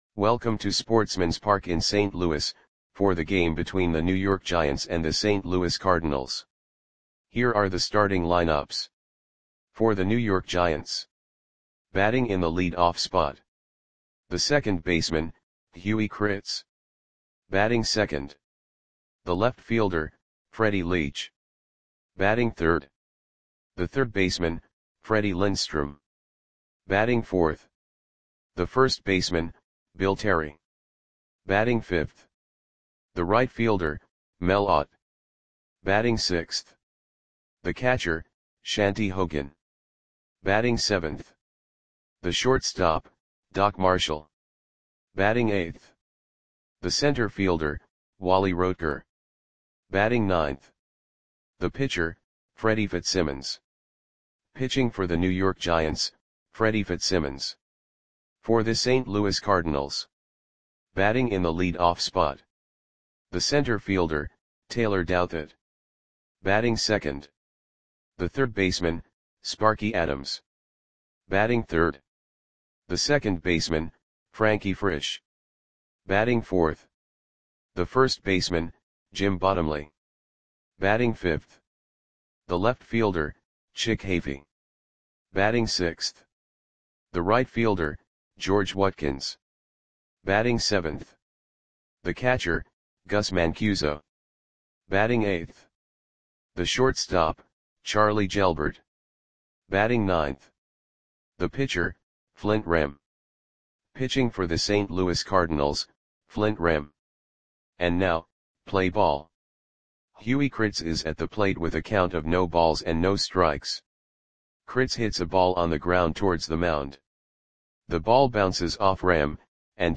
Audio Play-by-Play for St. Louis Cardinals on August 20, 1930
Click the button below to listen to the audio play-by-play.